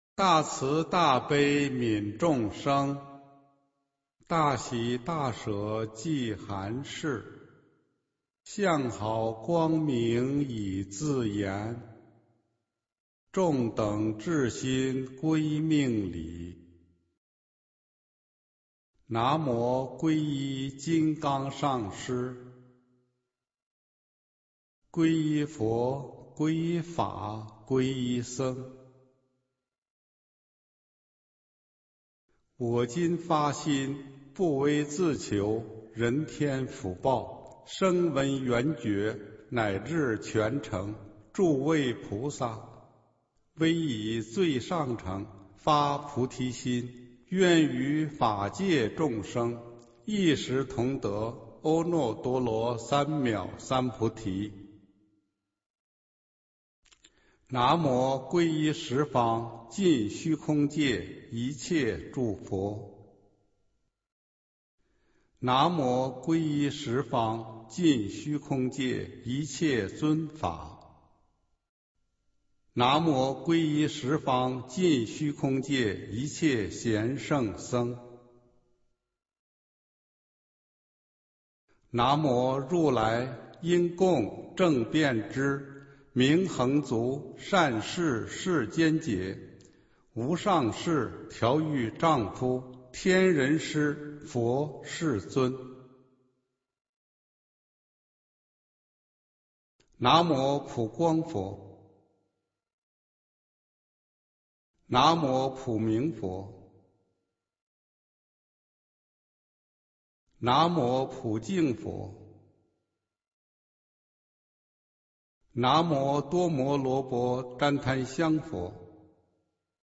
佛音 经忏 佛教音乐 返回列表 上一篇： 六字大明咒(唱诵版